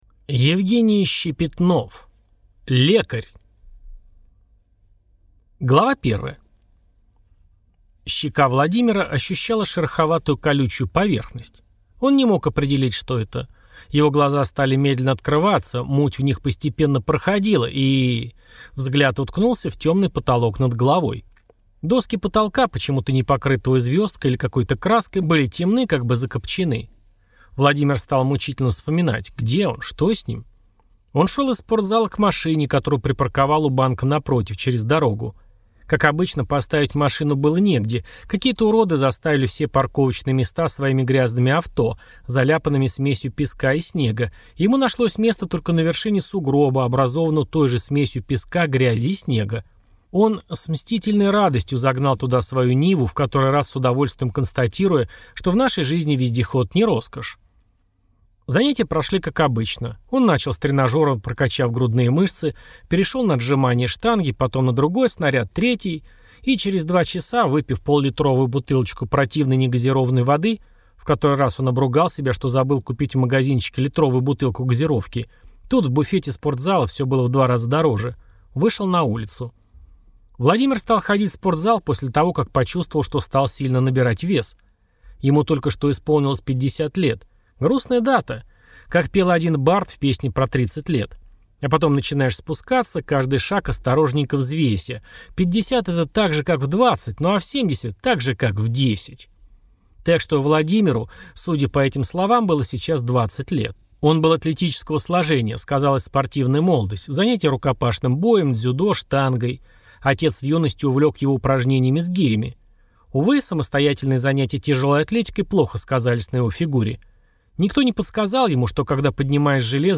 Аудиокнига Лекарь - купить, скачать и слушать онлайн | КнигоПоиск